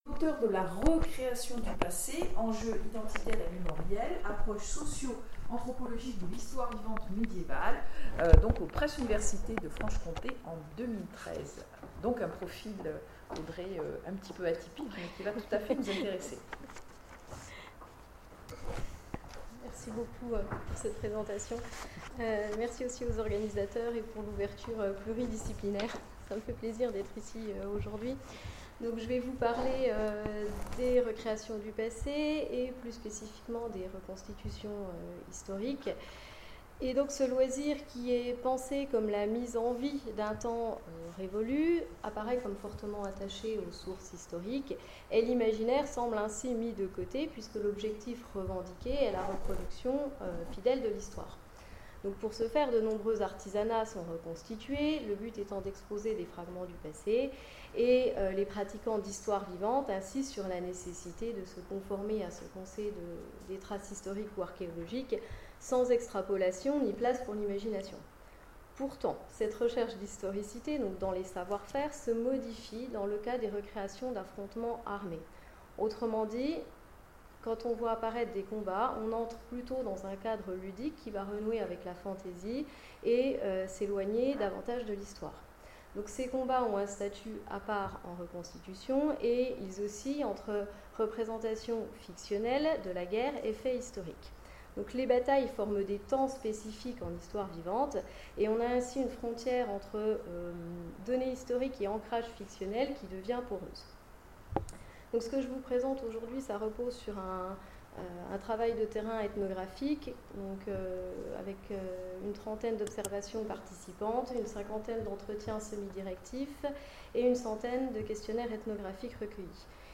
Colloque universitaire 2018 : L’expérience ludique de l’histoire. L’exemple des combats en reconstitution historique
Mots-clés Histoire Conférence Partager cet article